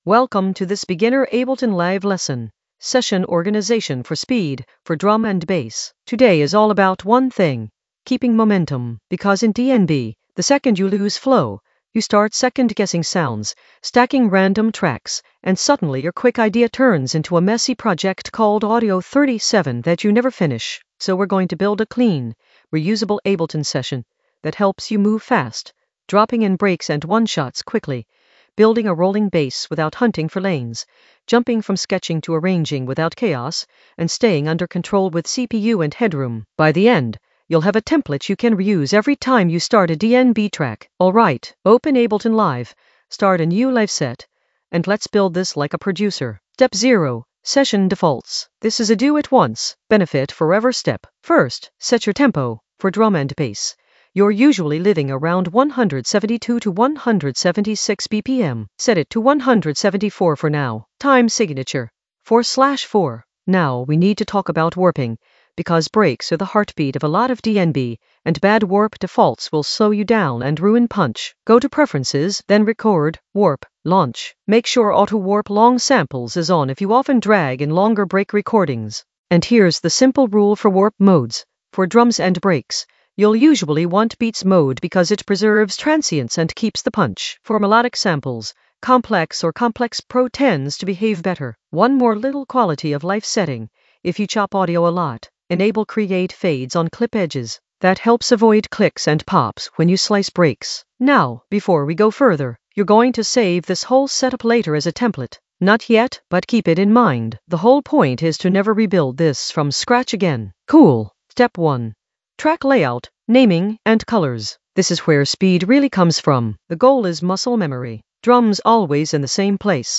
An AI-generated beginner Ableton lesson focused on Session organisation for speed in the Workflow area of drum and bass production.
Narrated lesson audio
The voice track includes the tutorial plus extra teacher commentary.